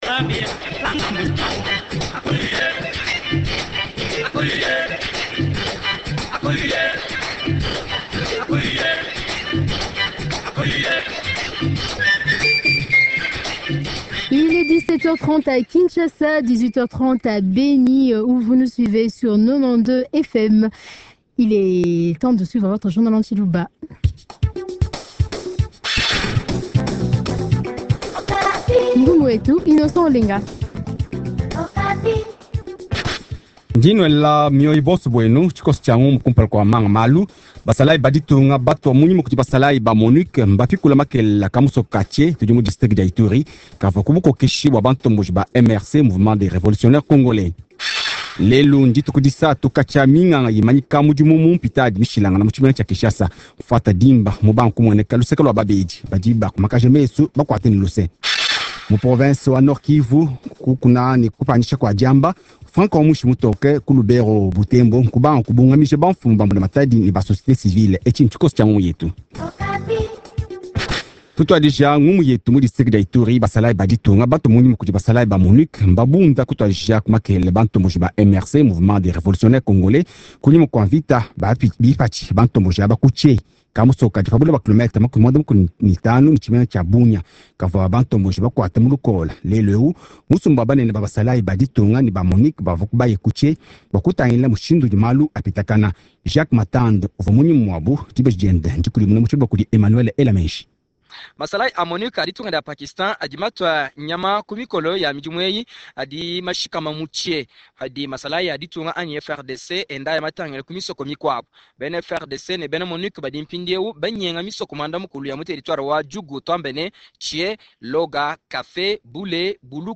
Journal Tshiluba